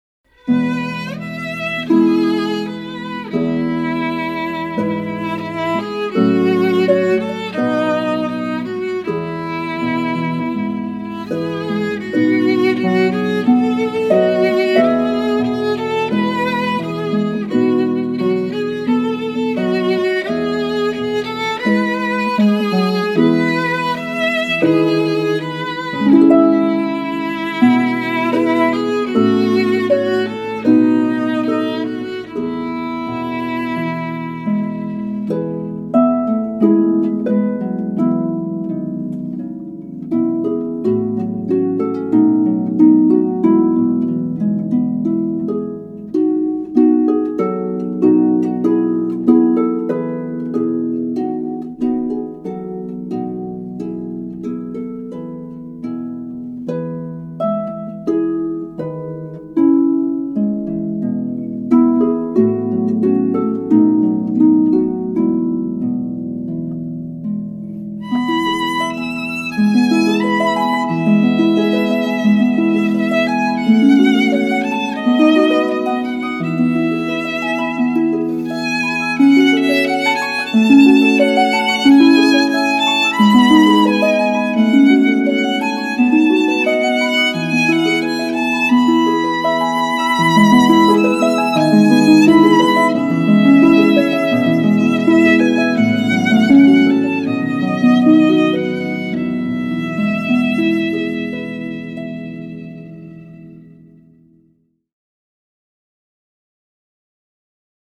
pedal harp and violin
violin